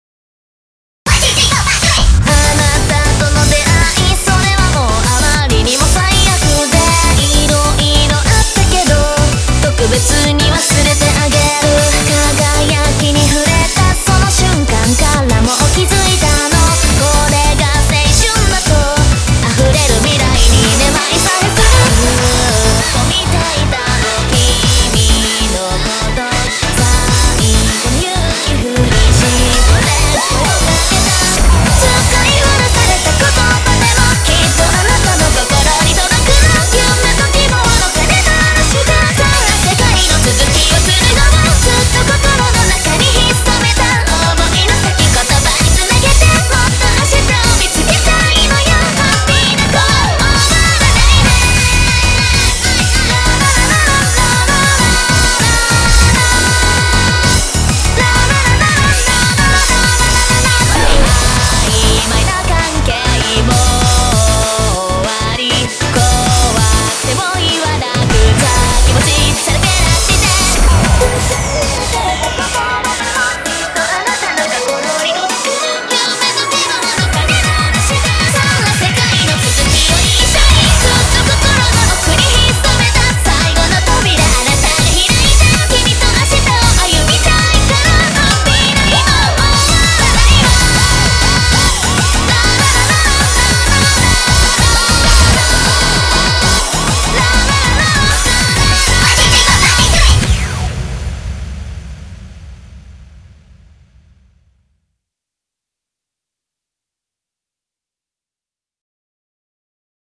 BPM196
Audio QualityPerfect (High Quality)
A hyperkinetic song with a lot of crash cymbals.